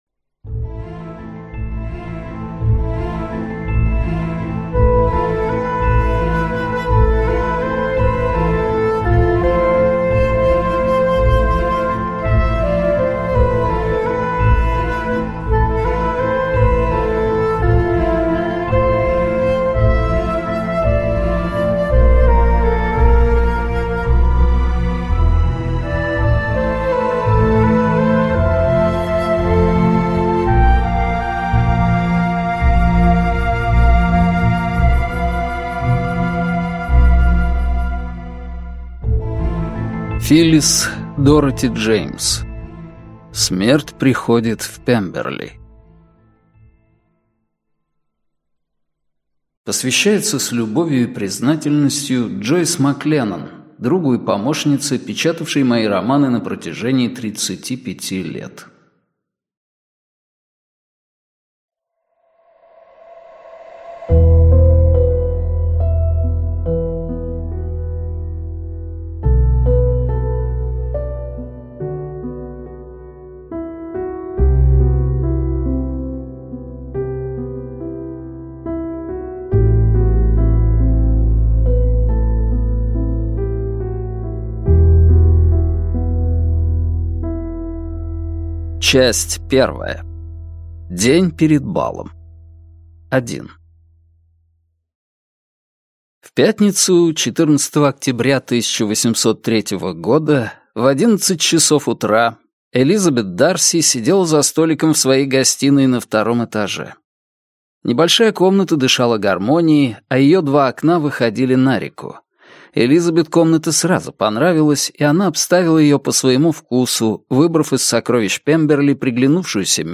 Аудиокнига Смерть приходит в Пемберли | Библиотека аудиокниг